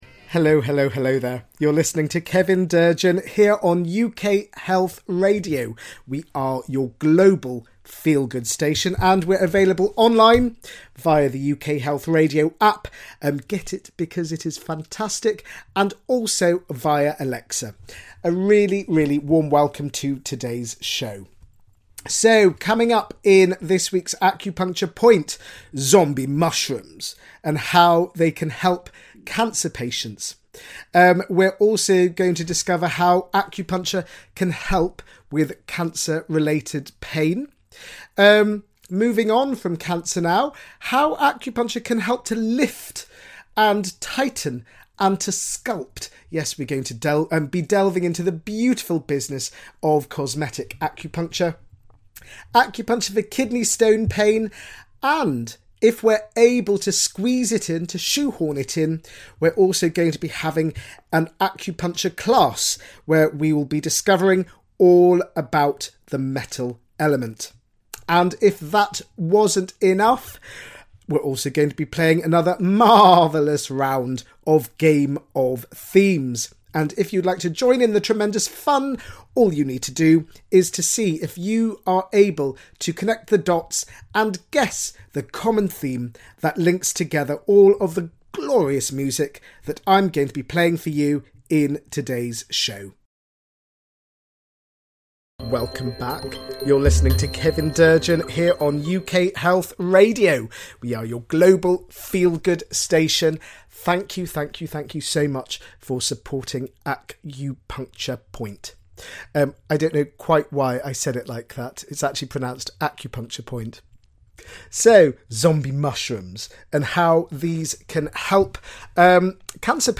He will also play some gorgeous music to uplift your soul and get your feet tapping with happiness.